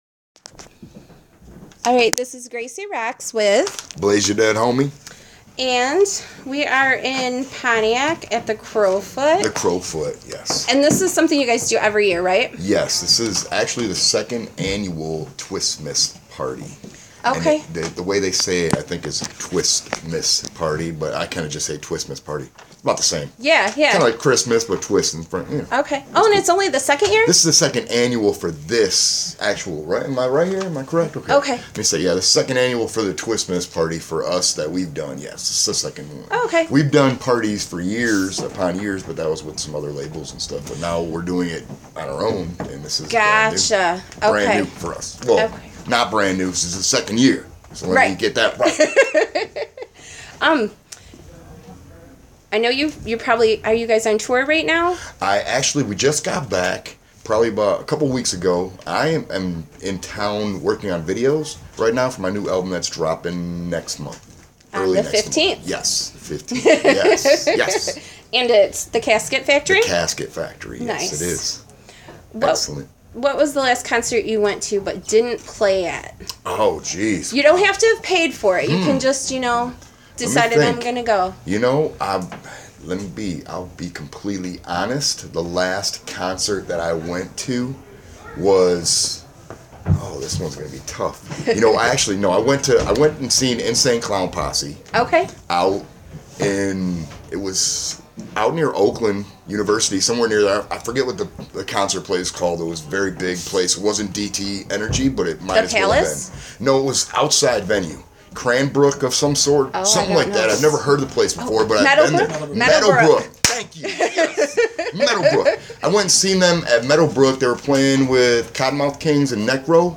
This interview was done while I was working with Echo Asylum – you should also check them out !